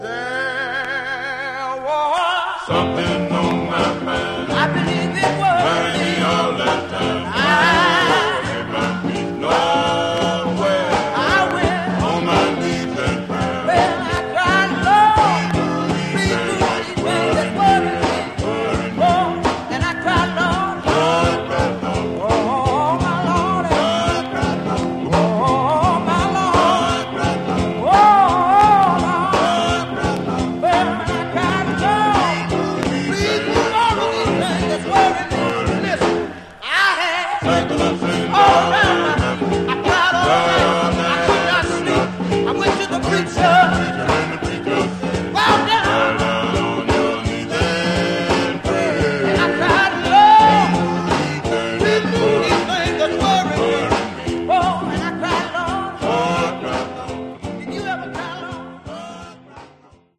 Genre: Gospel
on lead vocals